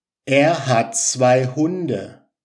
แอร์ ฮัท ซไว ฮุน-เด่อะ